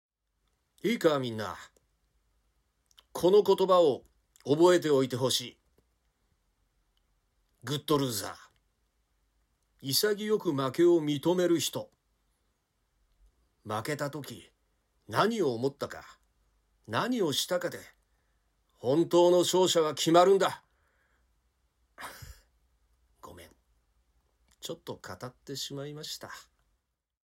ボイスサンプル
啓発する男性